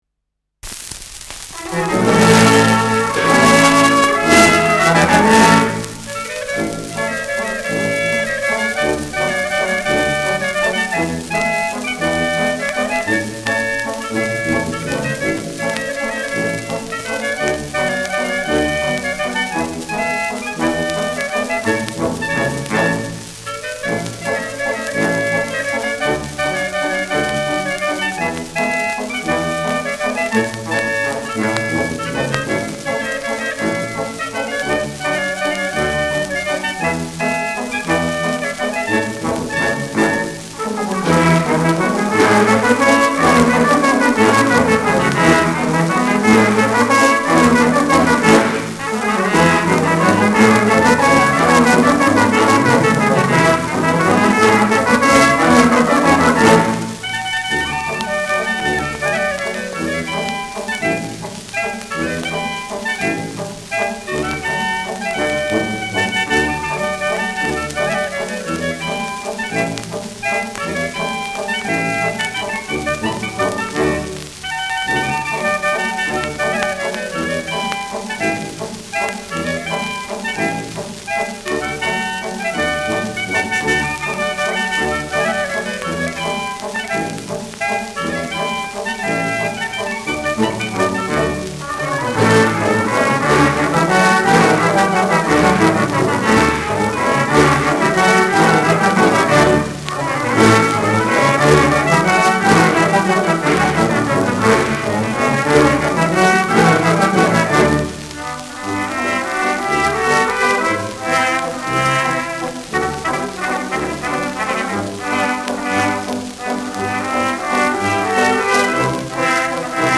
Schellackplatte
leichtes Rauschen : präsentes Knistern : leichtes „Schnarren“